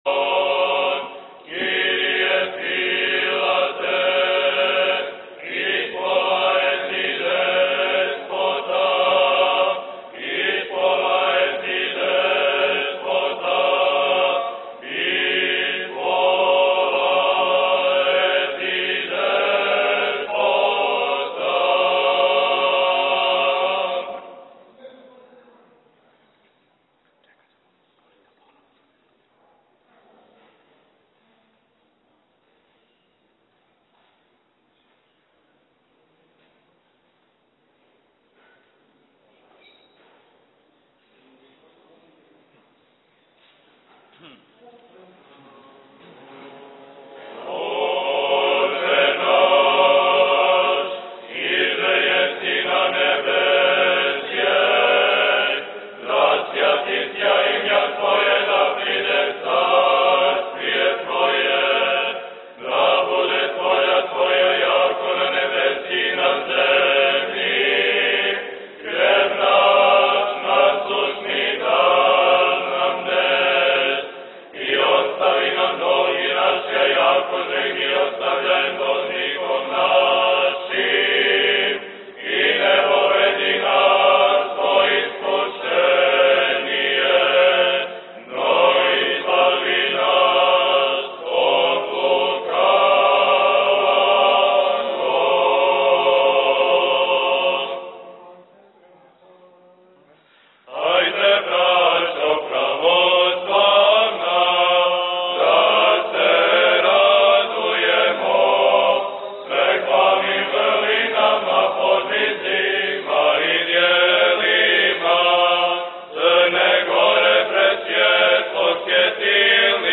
Svecana-duhovna-akademija-kripta-Lucindan-2024.m4a